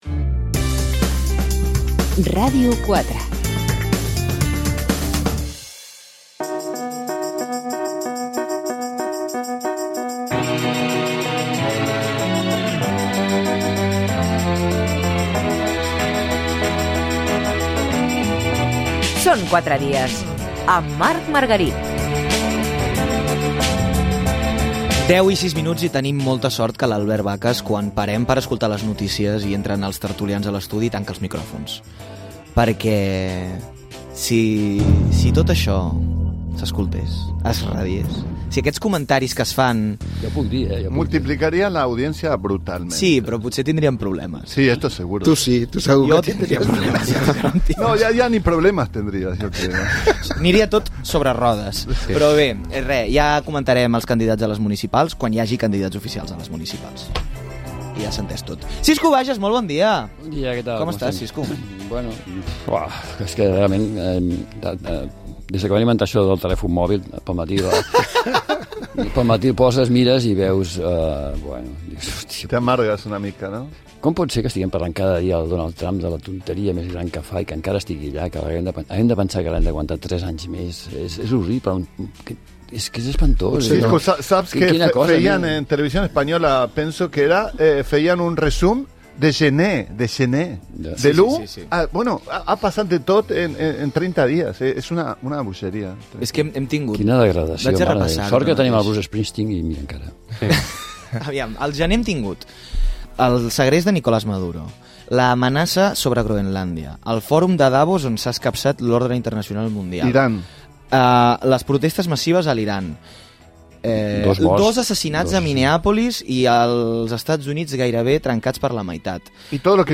Tertúlia setmanal